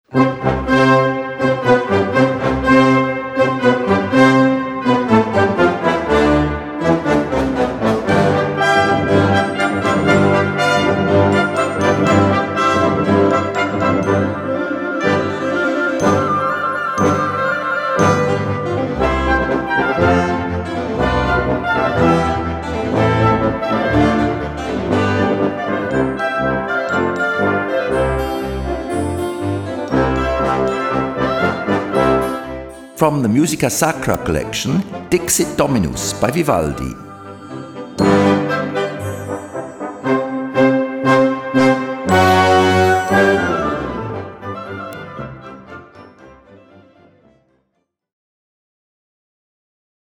Gattung: Kirchenmusik
Besetzung: Blasorchester